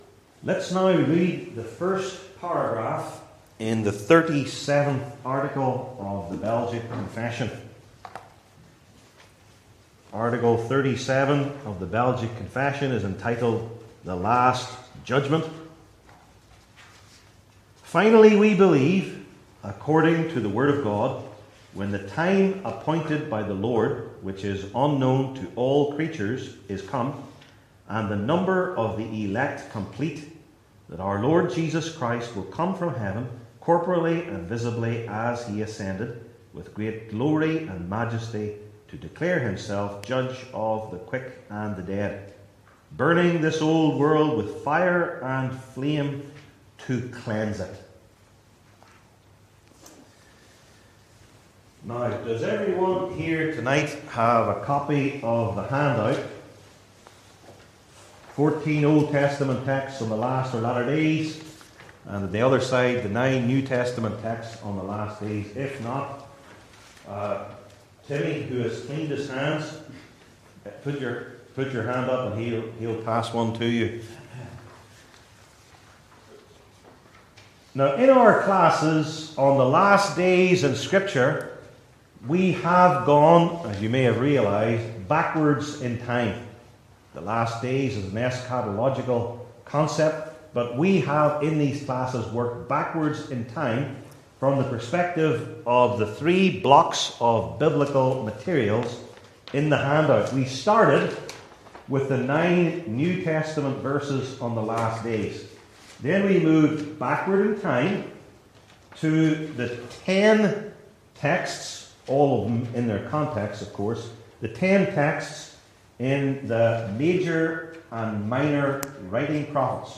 Series: Belgic Confession 37 , The Last Judgment Passage: Genesis 49:1-18 Service Type: Belgic Confession Classes